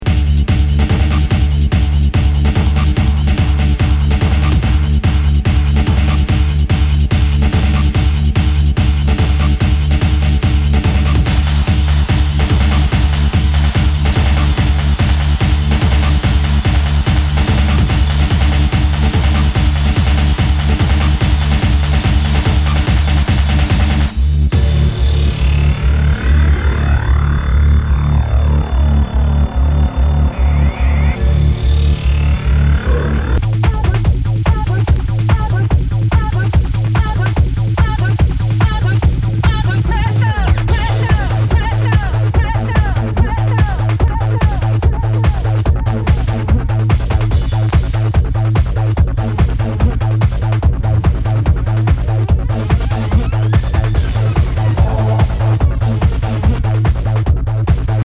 HOUSE/TECHNO/ELECTRO
ハード・ハウス！
ジャケにスレキズ、ヨゴレ、破れあり・全体にチリノイズが入ります